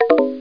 Soundscape: Paranal software sounds
The software of each instrument on Paranal plays certain sounds when there is some action required by the operators, or something goes wrong, or something was successful. These audio feedbacks are very useful because the staff don’t have to stare at the screens all the time.
Soundscape Mono (wav)
ss-paranal-software-guiding-start_mono.mp3